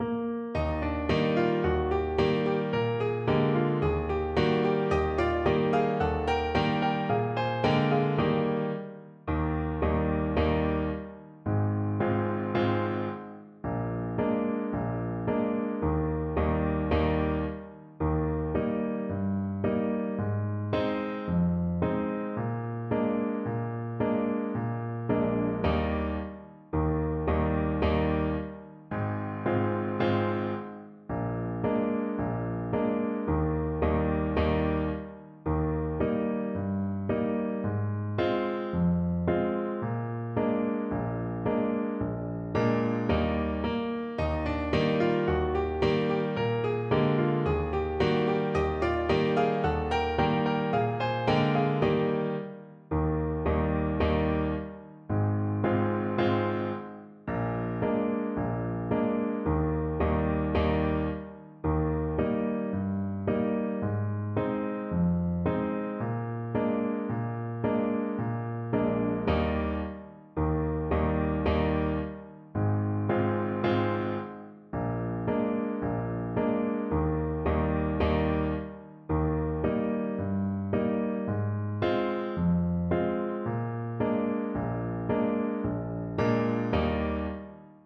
4/4 (View more 4/4 Music)
Allegro Moderato = c. 110 (View more music marked Allegro)
Traditional (View more Traditional Trumpet Music)